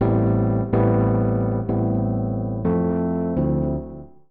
PIANO014_VOCAL_125_A_SC3(L).wav